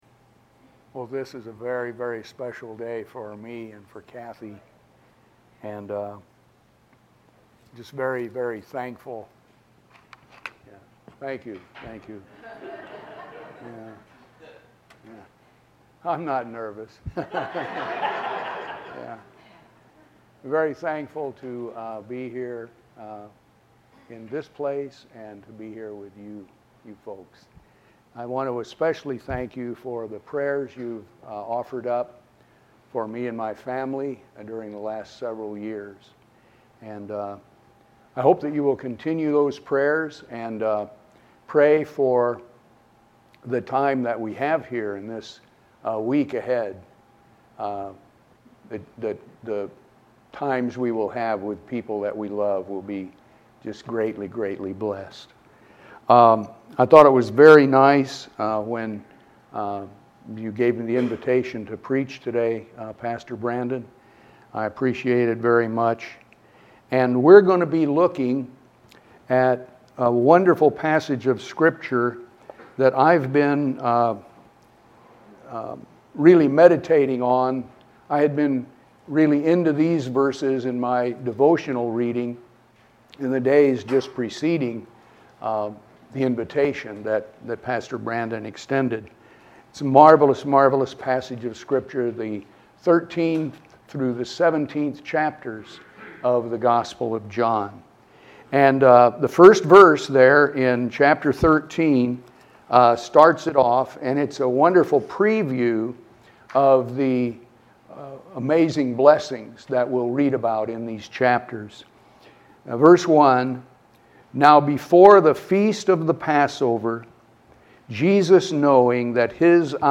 Title: Love and Hope from Jesus Text: John 13-17 Sermon Summary: 1. Jesus shows His wonderful love and gives us powerful reasons to love Him.